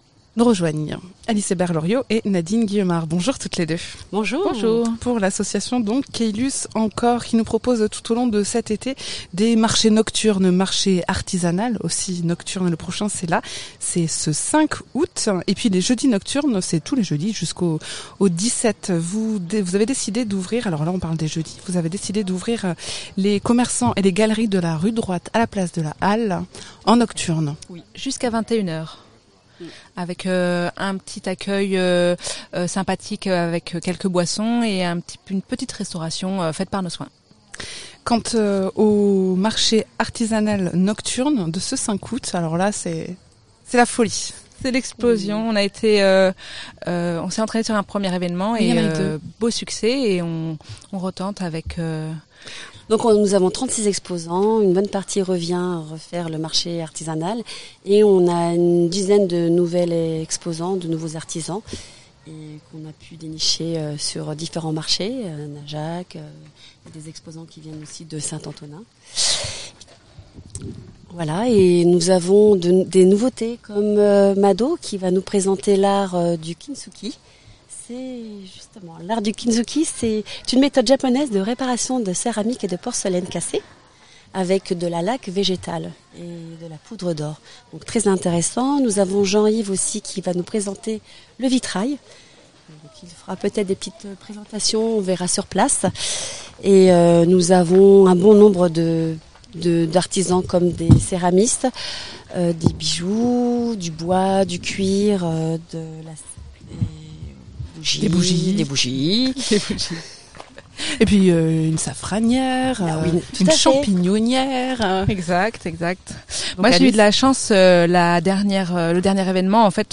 Un mag en extérieur au bord du lac de Caylus à propos des jeudis nocturnes et du marché artisanal de l’association Caylus en Cor.
Interviews